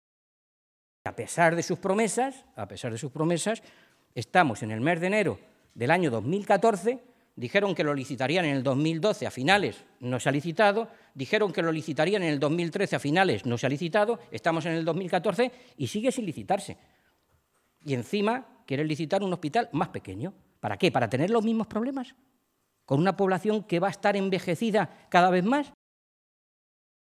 Fernando Mora, portavoz de Sanidad del Grupo Parlamentario Socialista
Cortes de audio de la rueda de prensa